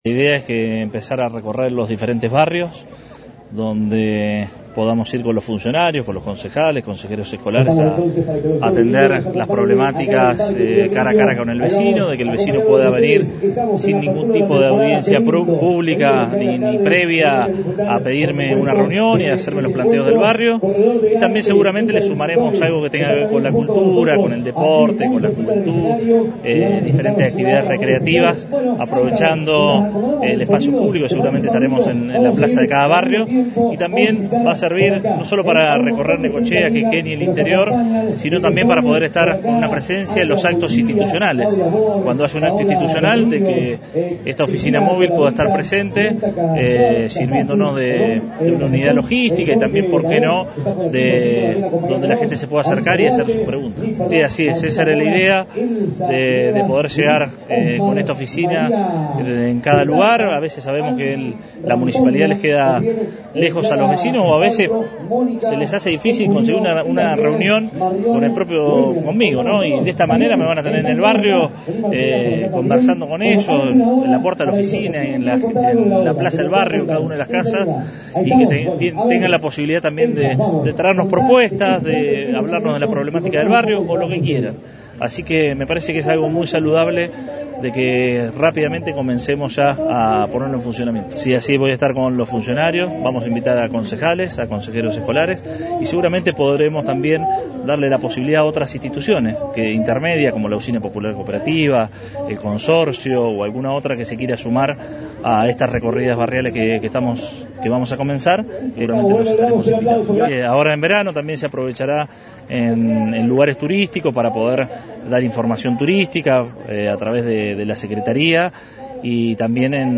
“La idea es atender las problemáticas cara a cara con el vecino, porque sabemos que la Municipalidad muchas veces les queda lejos”, señaló el jefe comunal durante la apertura de la temporada, donde el ómnibus se presentó en sociedad.
20-12-AUDIO-Arturo-Rojas.mp3